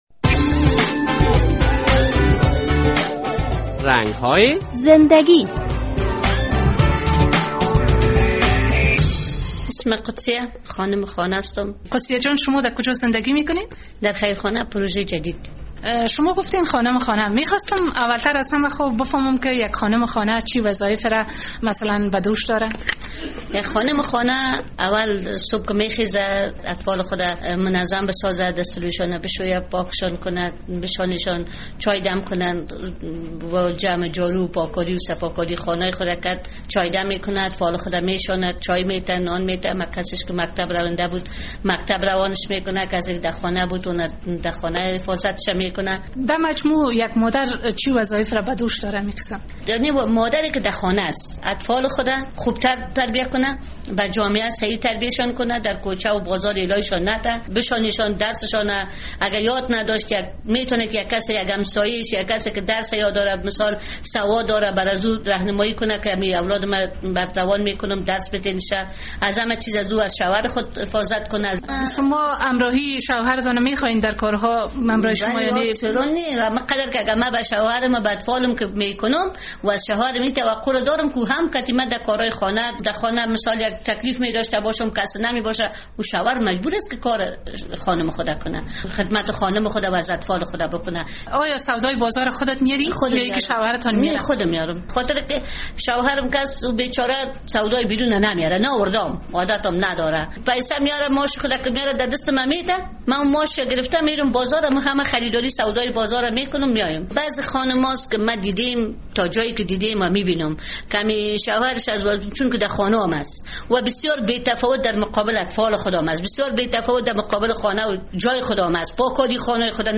در این برنامهء رنگ های زنده گی خبرنگار رادیو آزادی با یک خانم خانه صحبت کرده است.